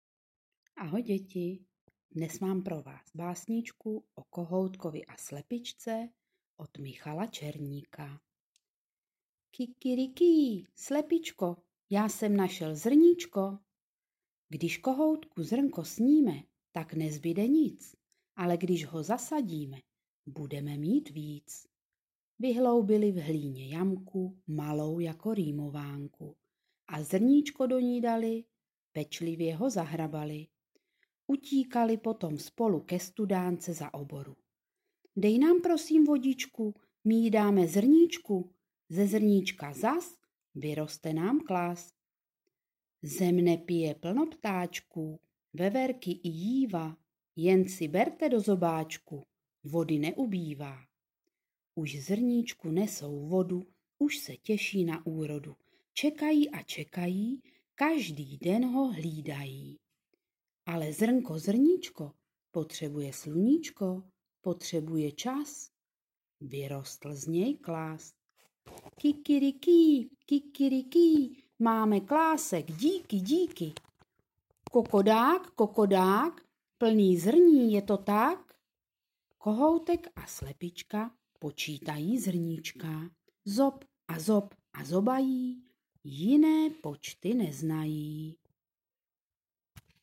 Básnička: O kohoutkovi a slepičce (nutné stáhnout do počítače a přehrát ve WMP)